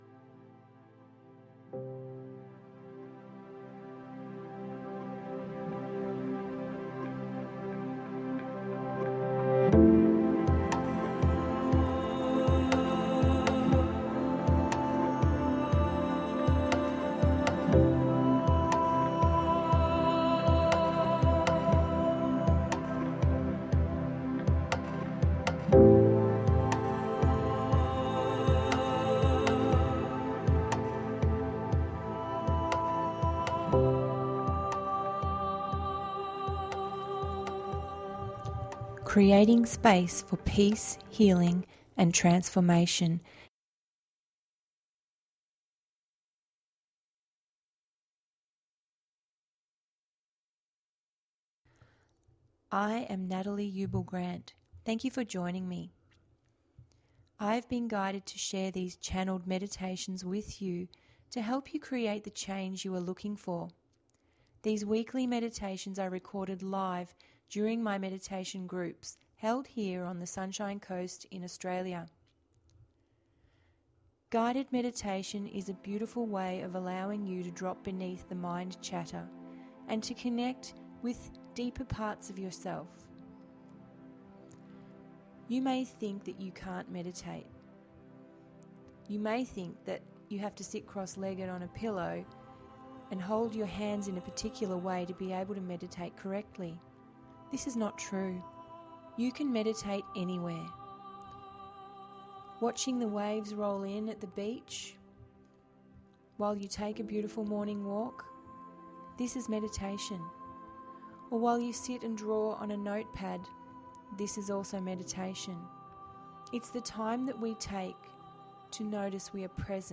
Healing with the Crystal Deva’s…075 – GUIDED MEDITATION PODCAST